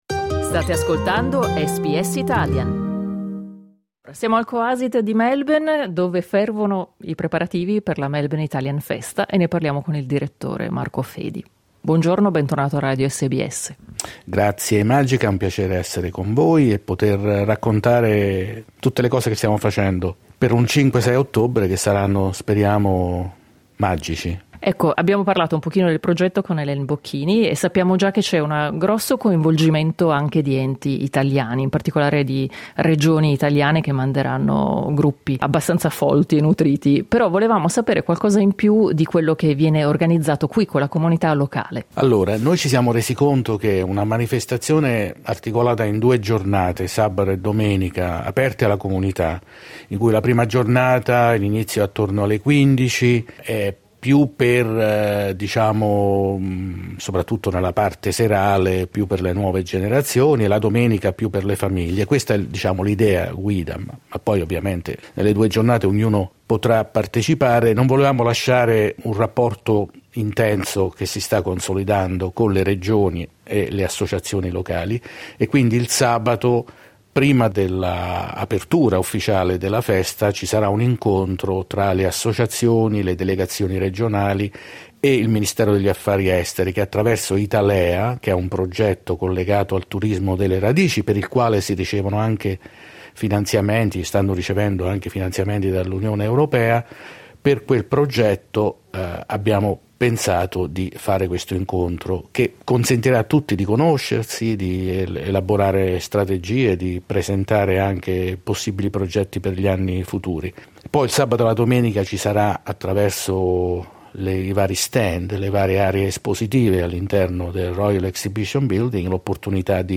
Clicca sul tasto "play" in alto per ascoltare l'intervista Alcuni partecipanti della Melbourne Italian Festa 2023.